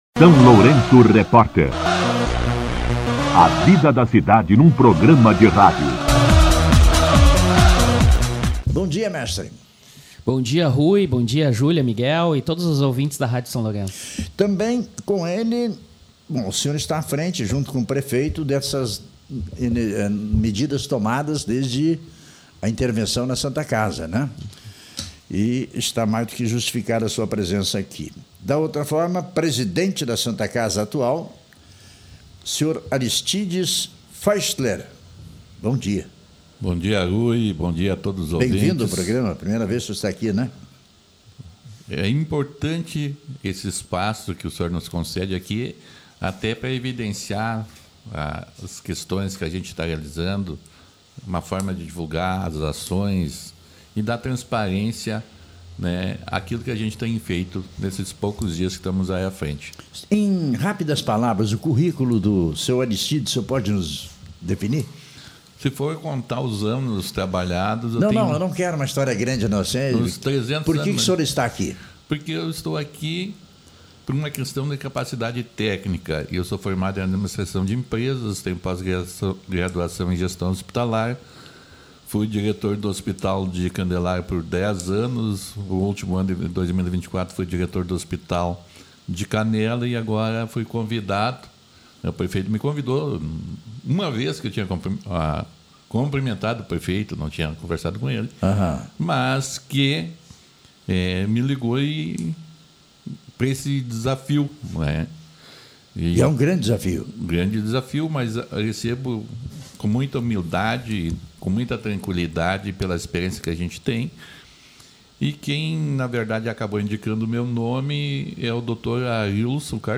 Entrevista com o secretário de Saúde, Diego Elias